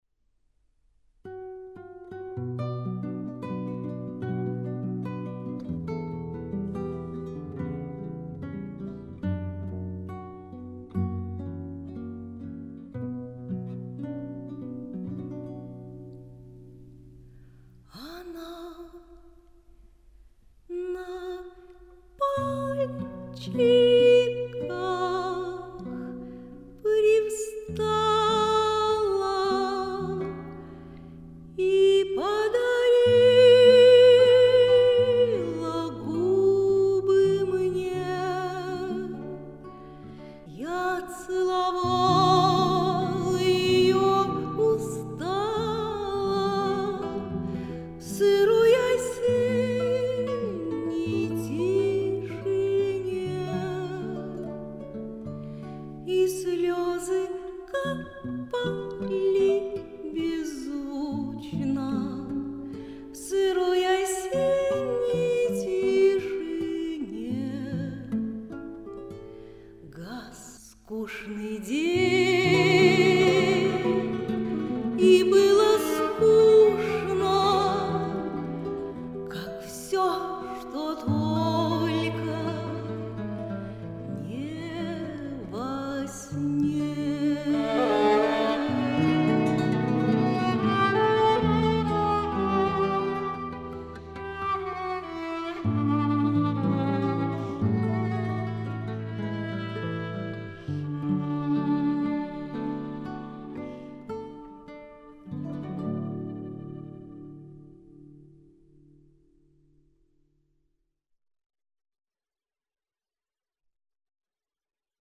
цыг. романс на ст.